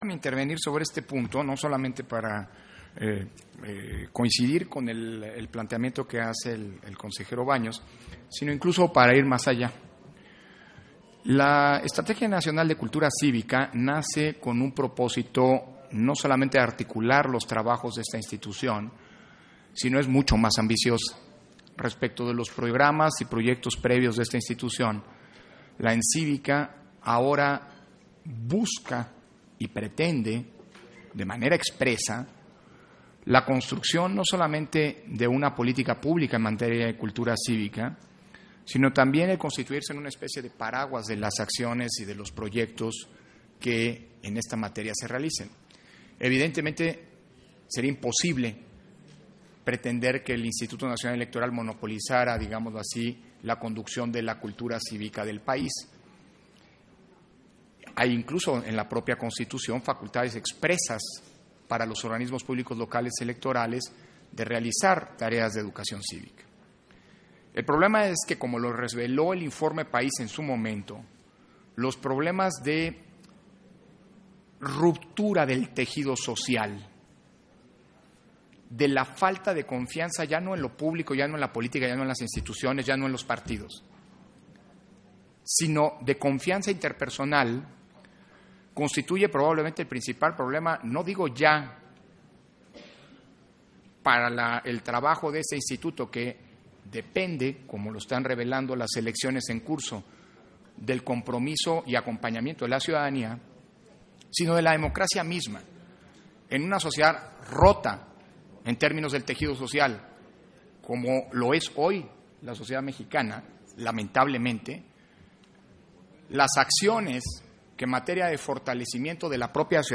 Intervención de Lorenzo Córdova, en Sesión Extraordinaria, del informe del Programa Anual 2018 de actividades de Implementación y Evaluación ENCCÍVICA